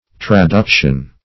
Traduction \Tra*duc"tion\, n. [L. traductio a transferring: cf.